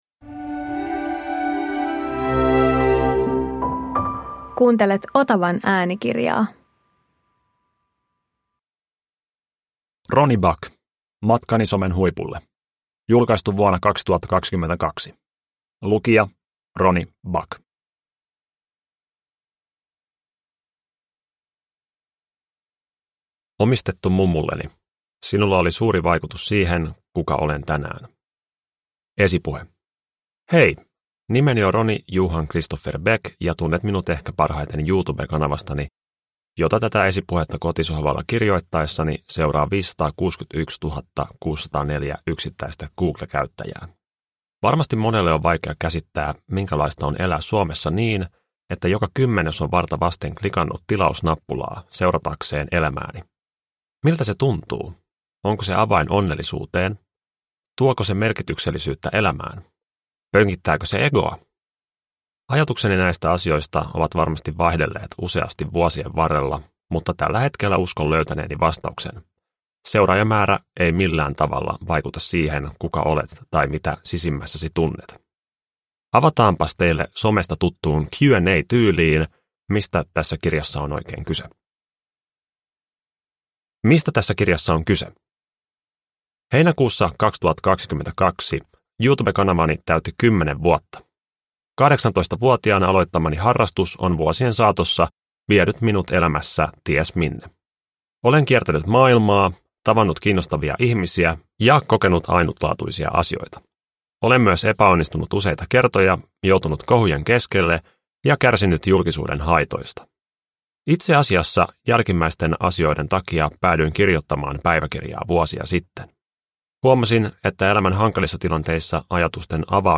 Matkani somen huipulle – Ljudbok – Laddas ner